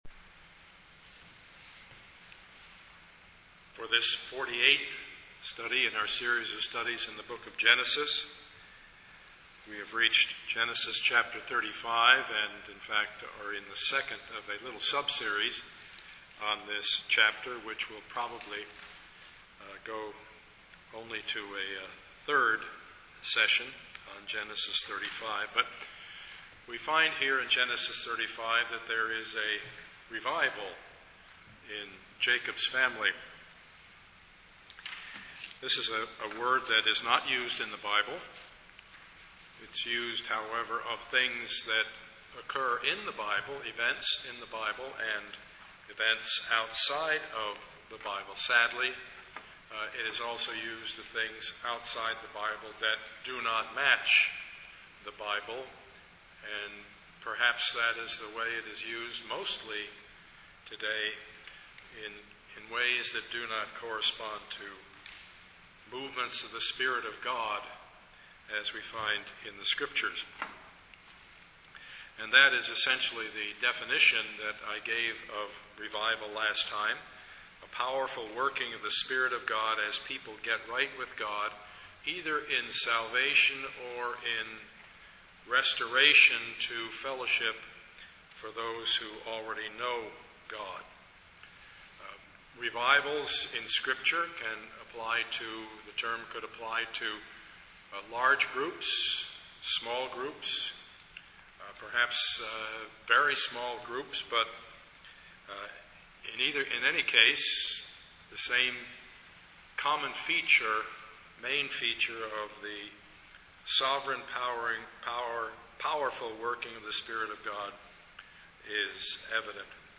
Passage: Genesis 35:1-15 Service Type: Sunday morning
Sermon